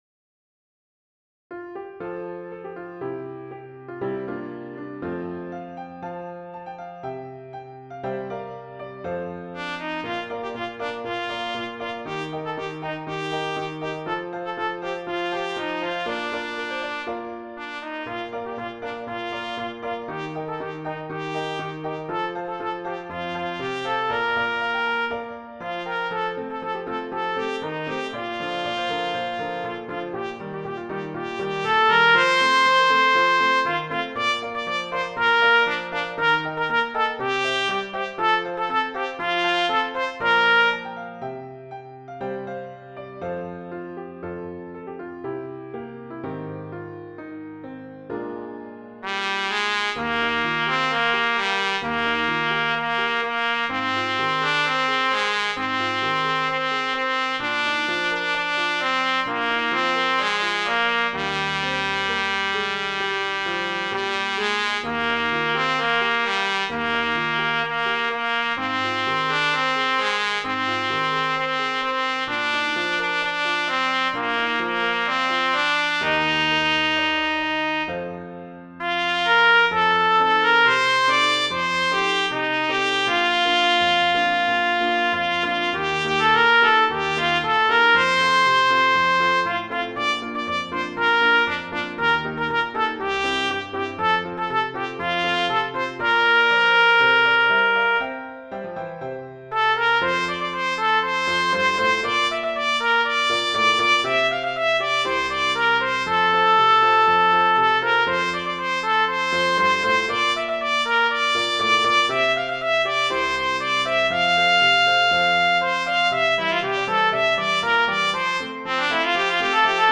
Christian, Gospel, Sacred.
set to a fast past, energetic jig.